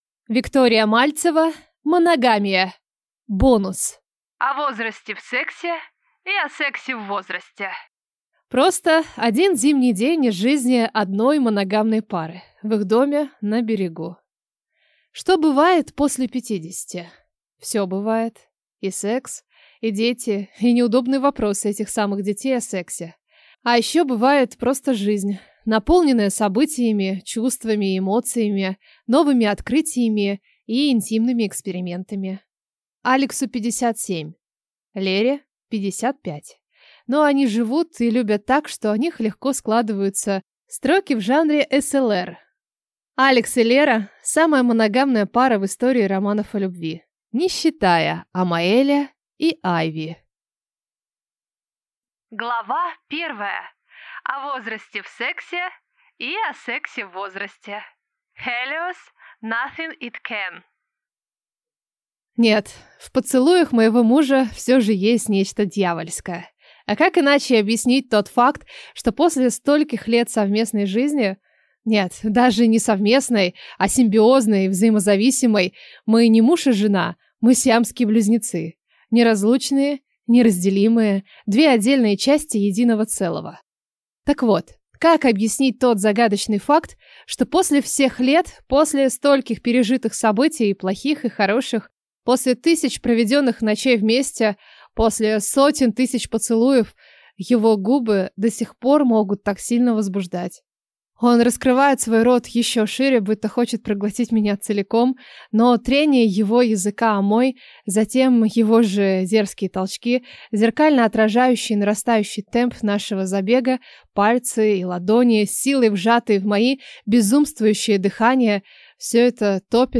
Аудиокнига Моногамия. Бонус. О возрасте в сексе и о сексе в возрасте | Библиотека аудиокниг